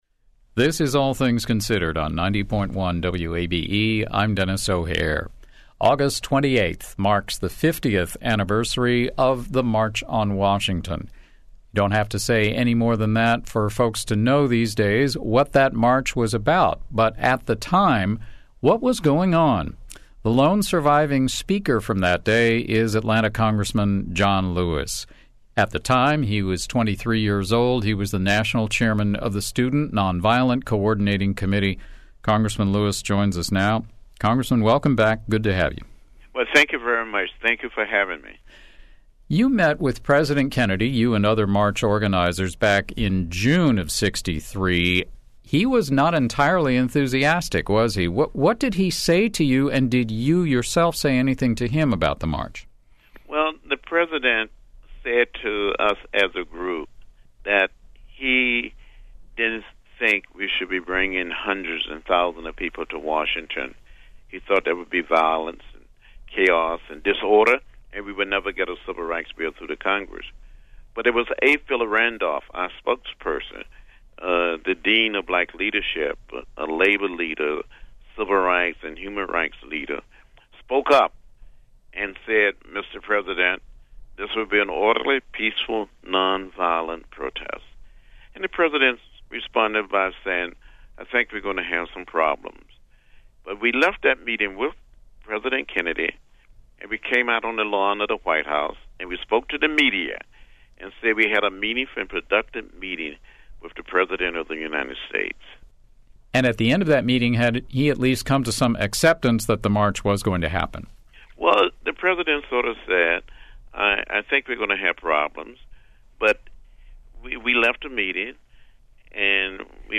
Remembering the March on Washington: A Talk with Atlanta Rep. John Lewis (D-Georgia) - WABE